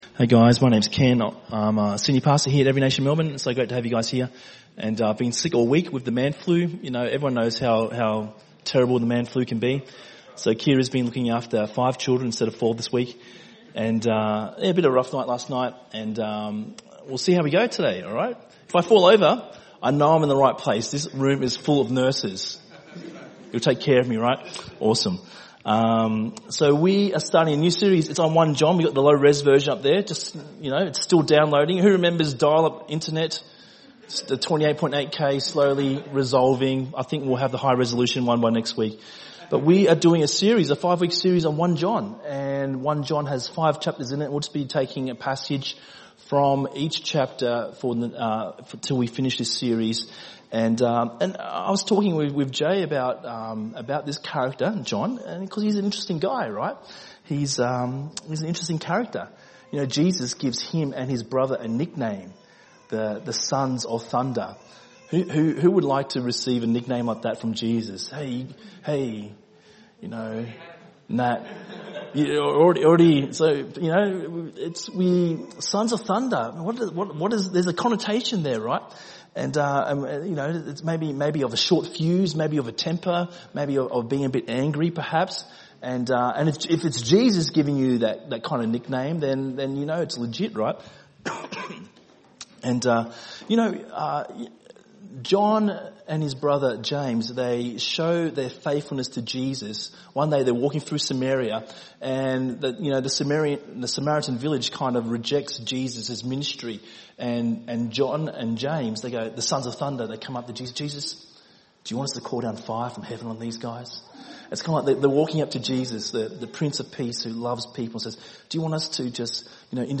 ENM Sermon